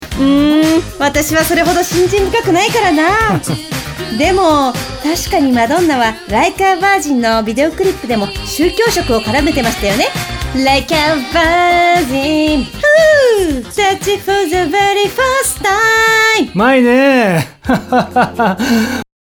Voix off
NEC (Pub)